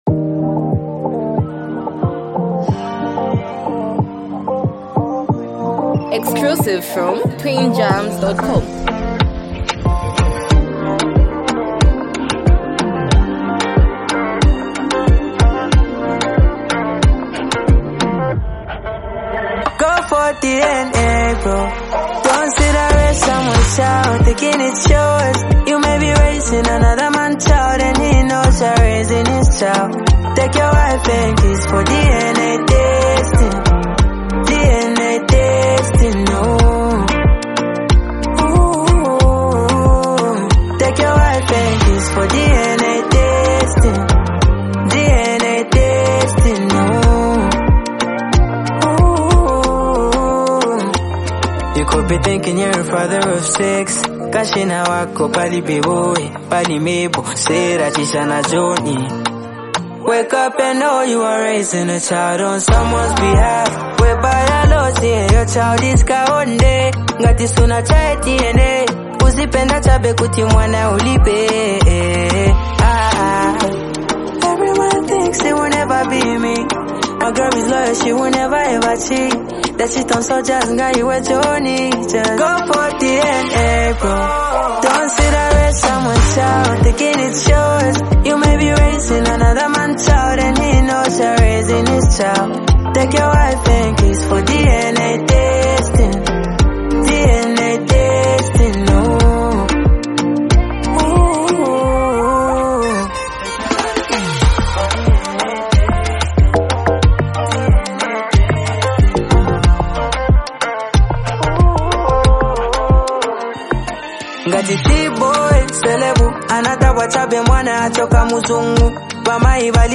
hard-hitting hip-hop track
Musically, the track is driven by a gritty hip-hop beat